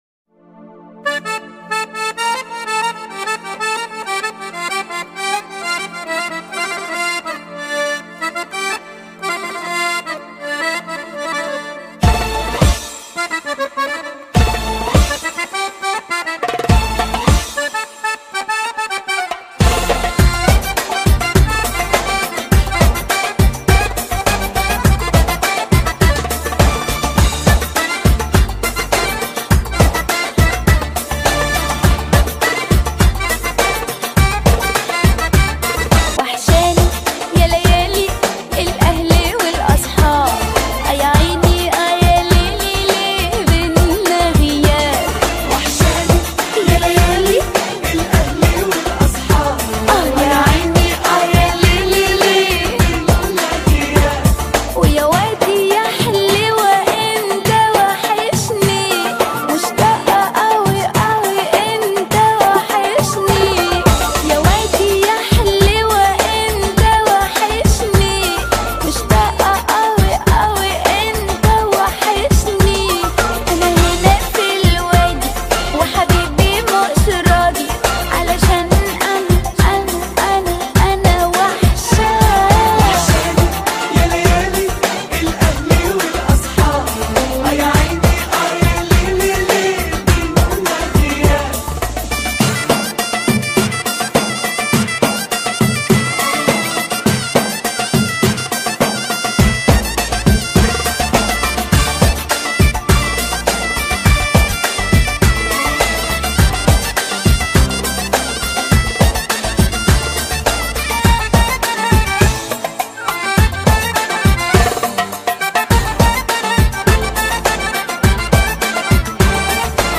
это яркая и зажигательная песня в жанре арабского попа